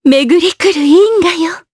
Yuria-Vox_Skill7_jp_b.wav